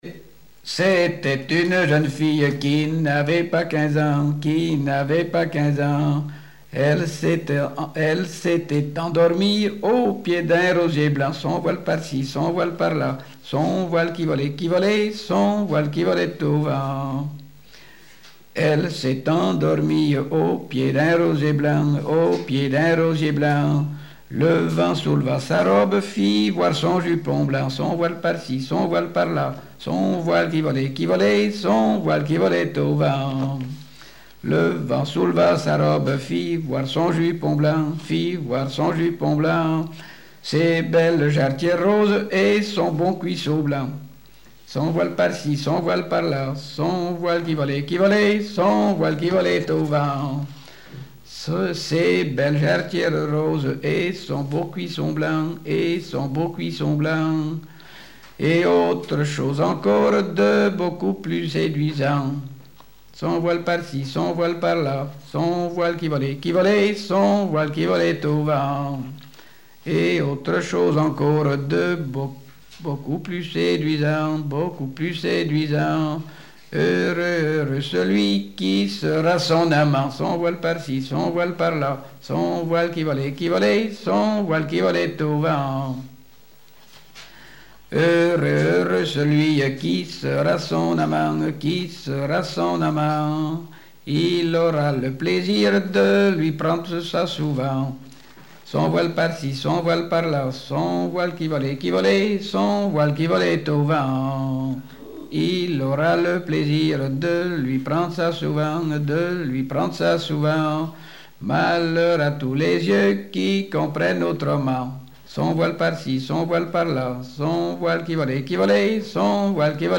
Genre laisse
Répertoire de chansons traditionnelles et populaires
Pièce musicale inédite